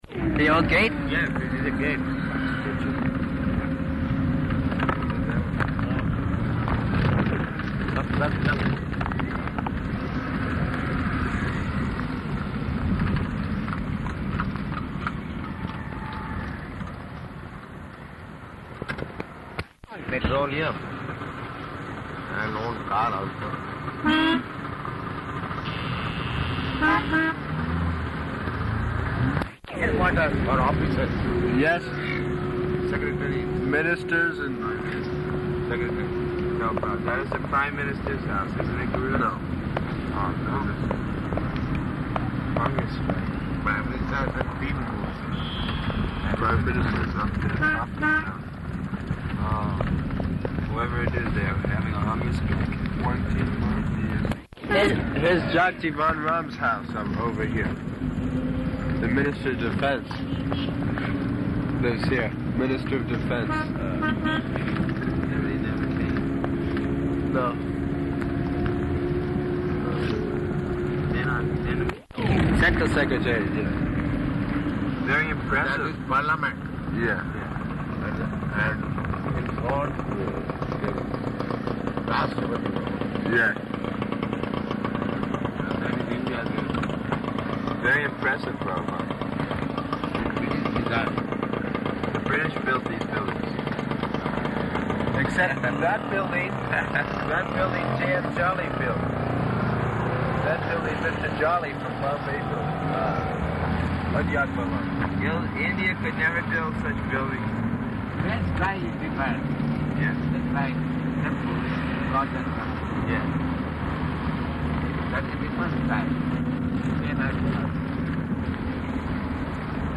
Conversation in Car
Location: Delhi